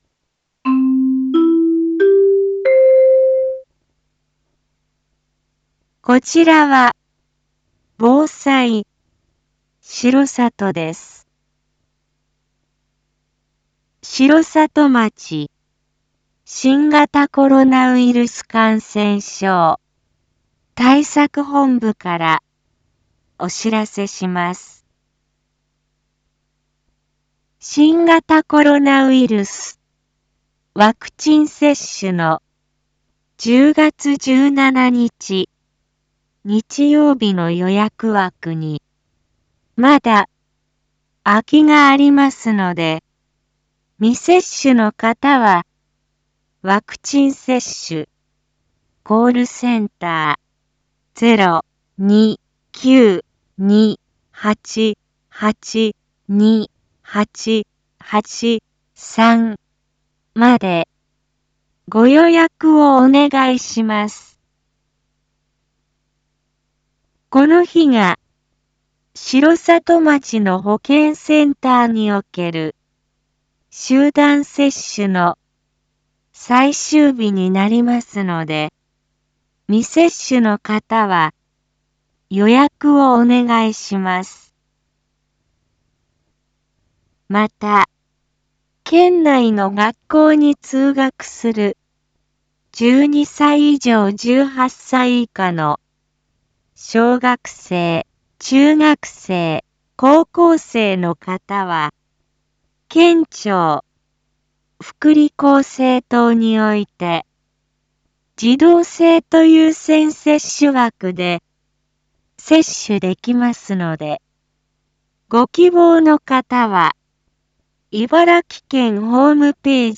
一般放送情報
Back Home 一般放送情報 音声放送 再生 一般放送情報 登録日時：2021-10-14 07:02:37 タイトル：R3.10.14 7時放送 インフォメーション：こちらは防災しろさとです。 城里町新型コロナウイルス感染症対策本部からお知らせします。